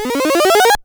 その他の効果音